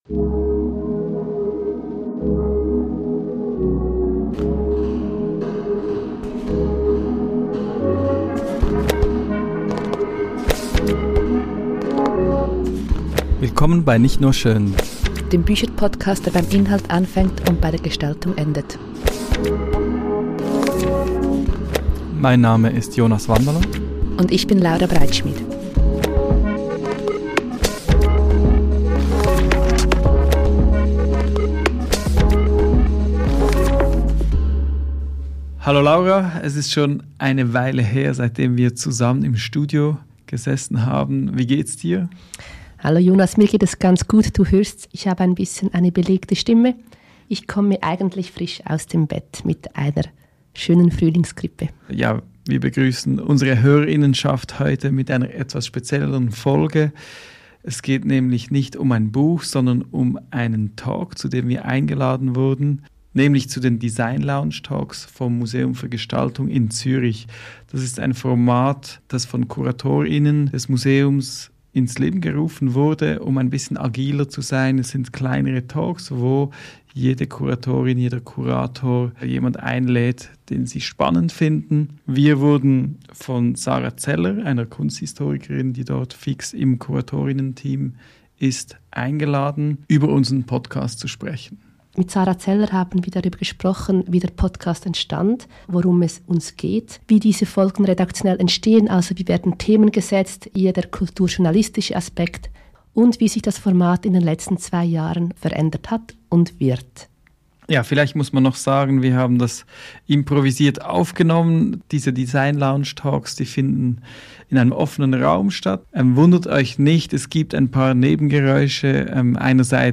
Das Museum für Gestaltung Zürich hat uns im Rahmen der Swiss Design Lounge zu einem Gespräch eingeladen. In diesem neu geschaffenen Format laden Kurator:innen des Museums Gestalter:innen ein, die sich an den Grenzen des klassischen Designs bewegen.